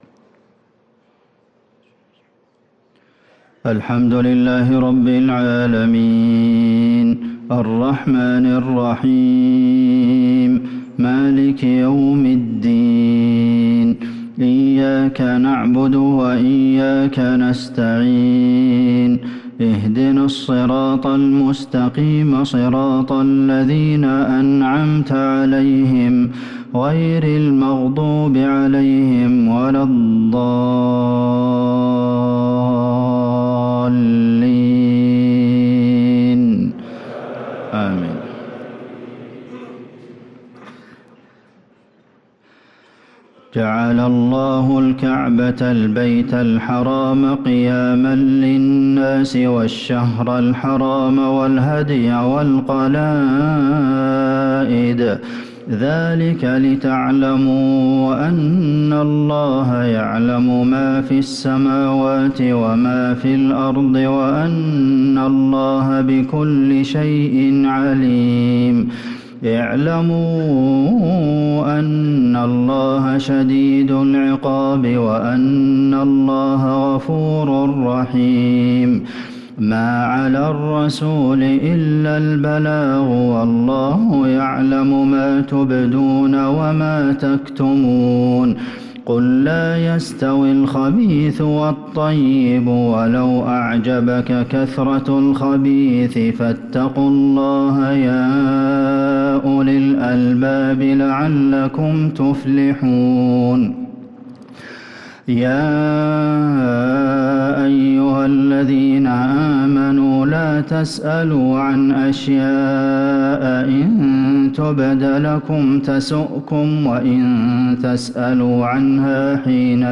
صلاة العشاء للقارئ عبدالمحسن القاسم 25 ذو القعدة 1444 هـ